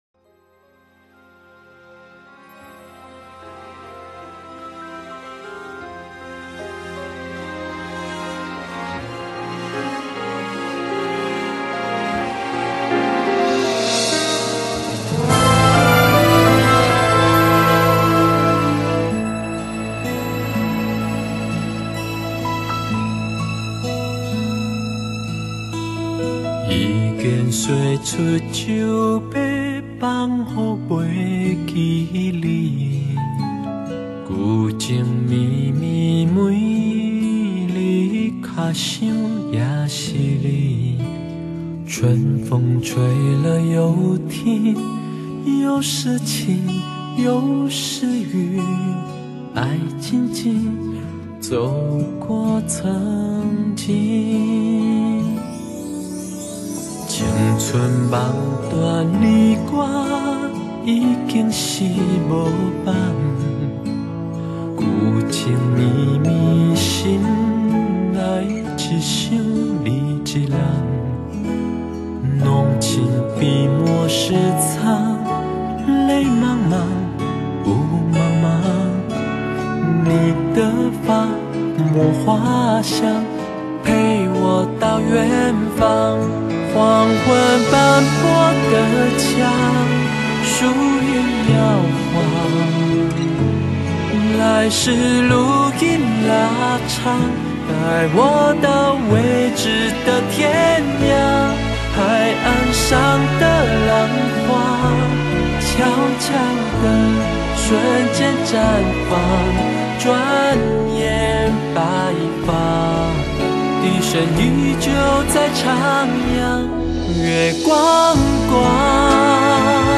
以自己的音樂特色，巧妙地結合了華語和臺語雙聲帶，唱出歌曲中最誠摯感人的音樂生命力。